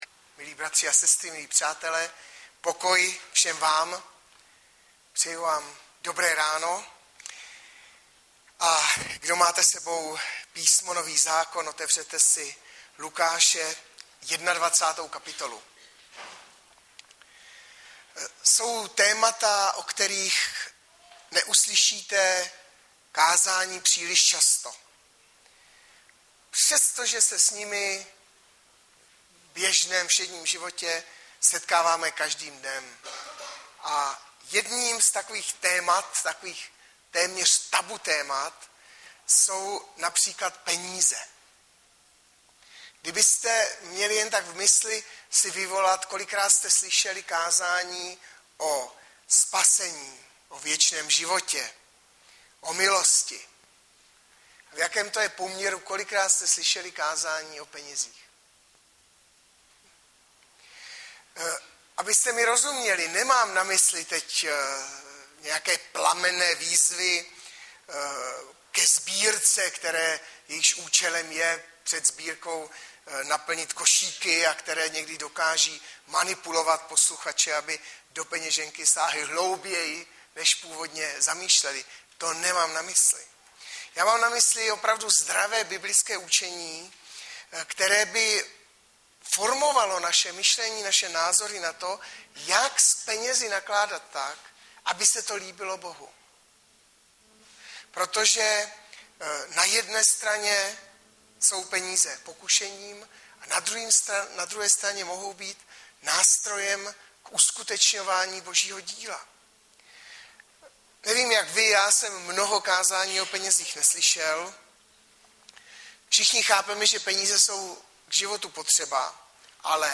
Hlavní nabídka Kázání Chvály Kalendář Knihovna Kontakt Pro přihlášené O nás Partneři Zpravodaj Přihlásit se Zavřít Jméno Heslo Pamatuj si mě  20.01.2013 - ZDRAVÝ VZTAH K PENĚZŮM - Luk 21,1-4 Audiozáznam kázání si můžete také uložit do PC na tomto odkazu.